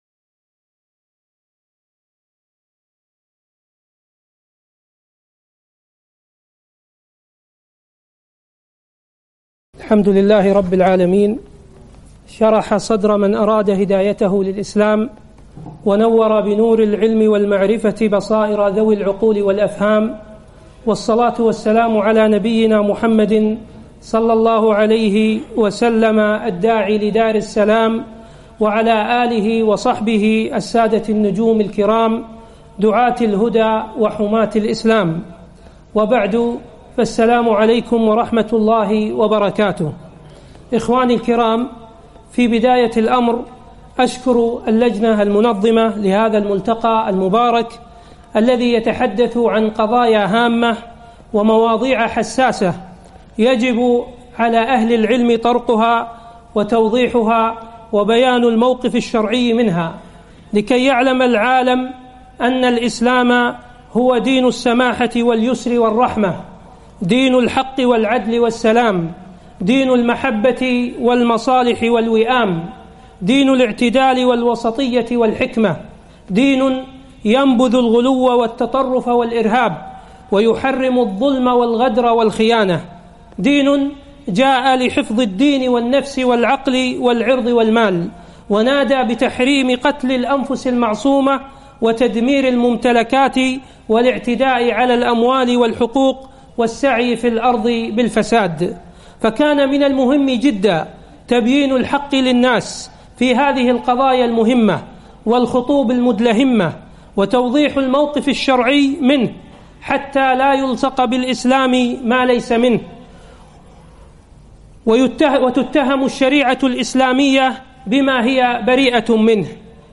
محاضرة - العلم الشرعي وأثره في محاربة الأفكار الهدامة